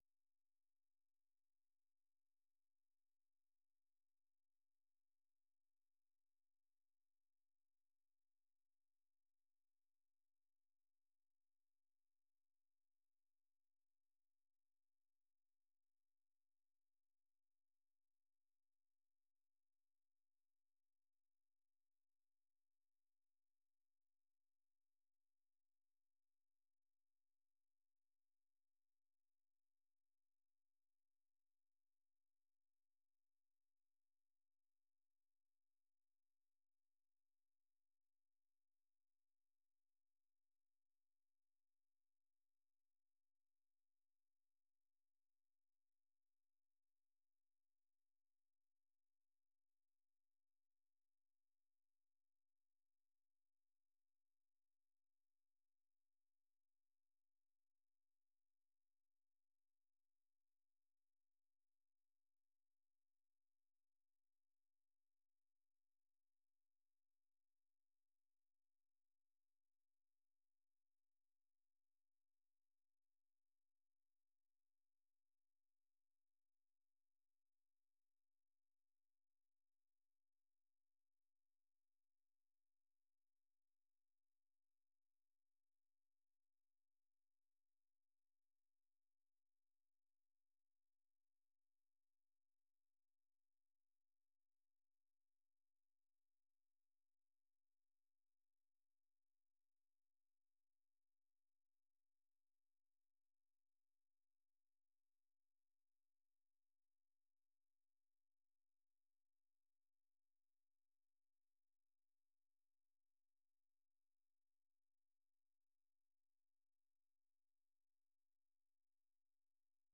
생방송 여기는 워싱턴입니다 아침
세계 뉴스와 함께 미국의 모든 것을 소개하는 '생방송 여기는 워싱턴입니다', 아침 방송입니다.